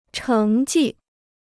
成绩 (chéng jì): achievement; performance records.
成绩.mp3